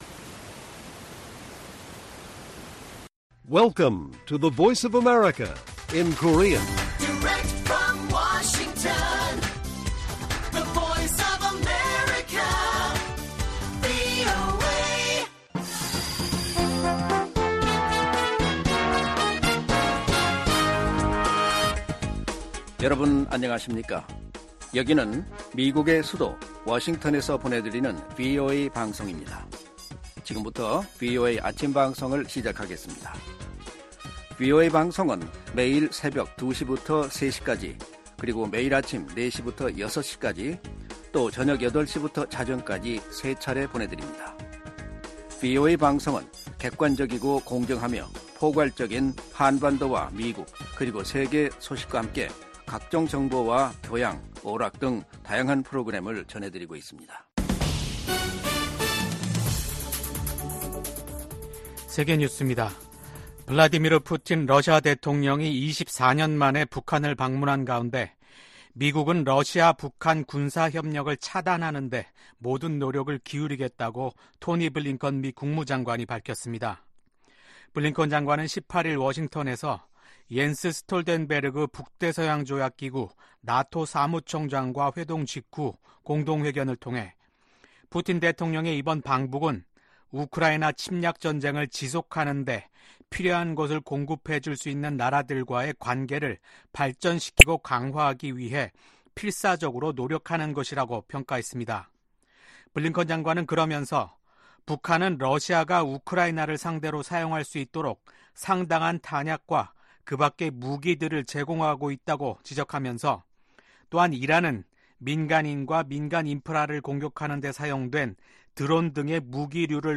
세계 뉴스와 함께 미국의 모든 것을 소개하는 '생방송 여기는 워싱턴입니다', 2024년 6월 20일 아침 방송입니다. '지구촌 오늘'에서는 이스라엘군이 레바논 공격을 위한 작전 계획을 승인하면서 확전 우려가 커지고 있는 소식 전해드리고 '아메리카 나우'에서는 조 바이든 대통령이 미국 시민권자와 결혼한 서류 미비 이민자들에게 미국 체류 허용과 시민권 취득 경로를 부여하는 새로운 행정명령을 발표한 소식 전해드립니다.